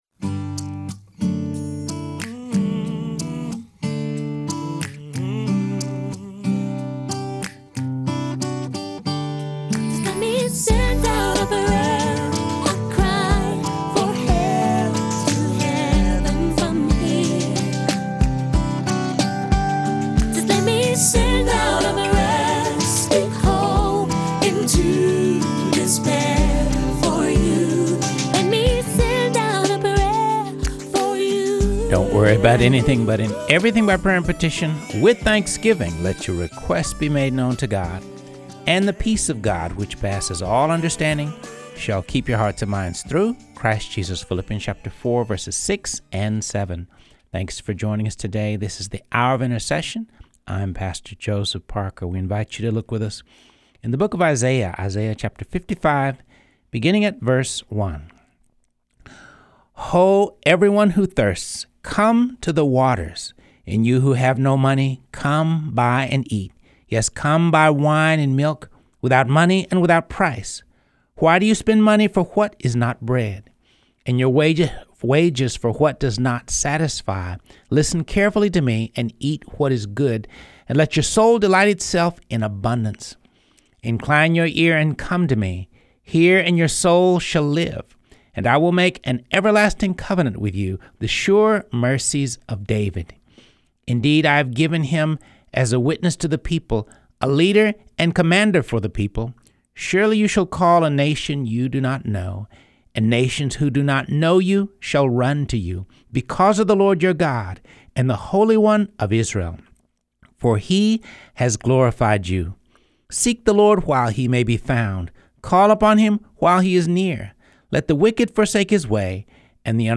Teaching: God Is in the Business of Fulfilling His Word